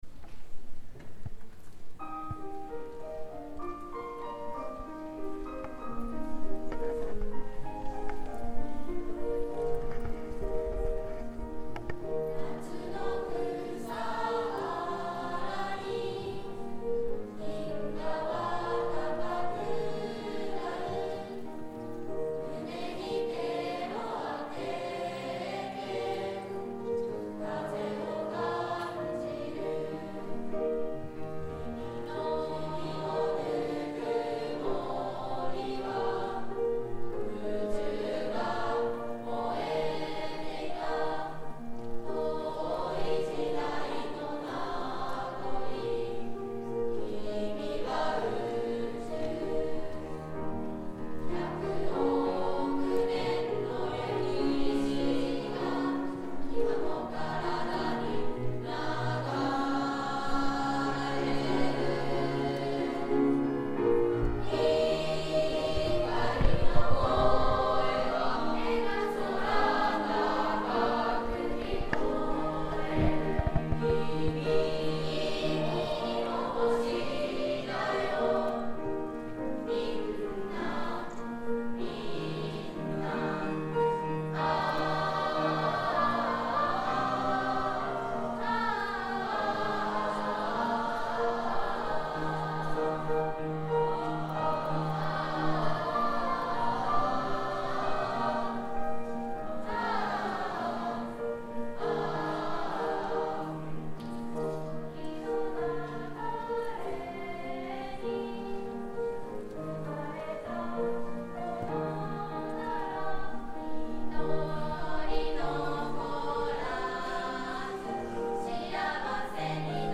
←クリックすると合唱が聴けます